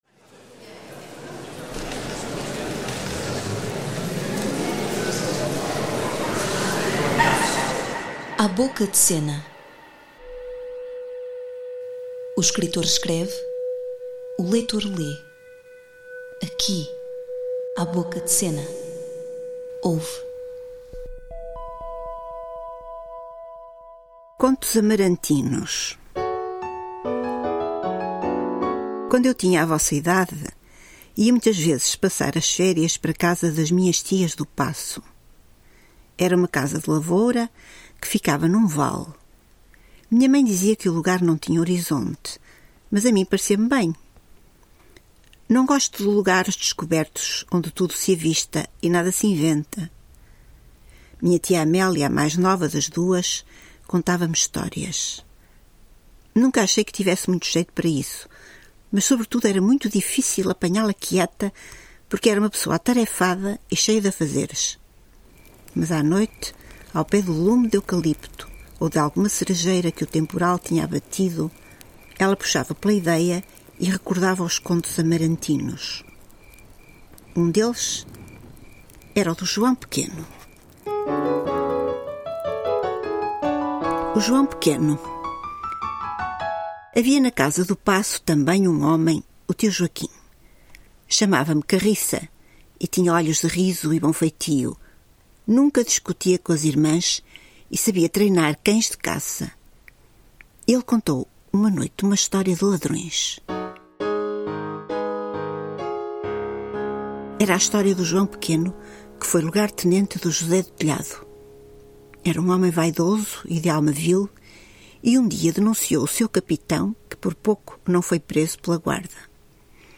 Música – fragmentos de: